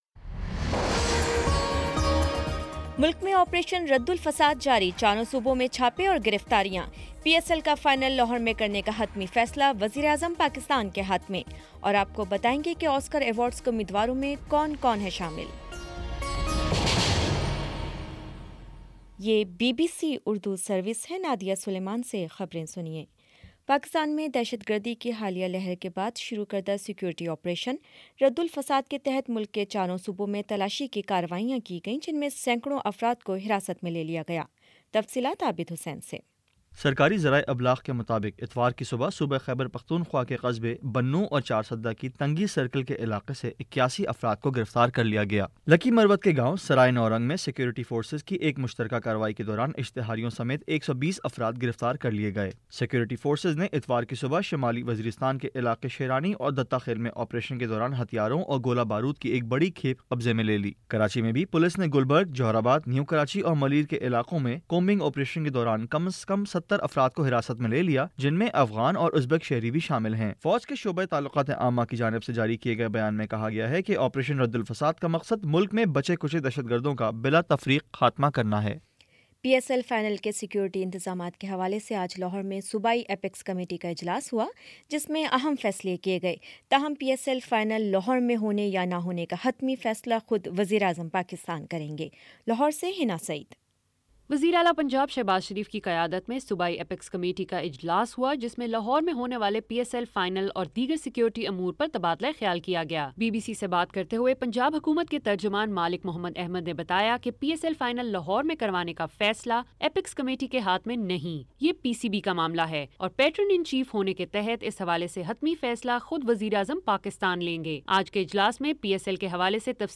فروری 26 : شام سات بجے کا نیوز بُلیٹن